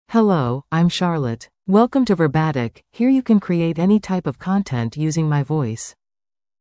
Charlotte — Female English (United States) AI Voice | TTS, Voice Cloning & Video | Verbatik AI
FemaleEnglish (United States)
Charlotte is a female AI voice for English (United States).
Voice sample
Listen to Charlotte's female English voice.
Charlotte delivers clear pronunciation with authentic United States English intonation, making your content sound professionally produced.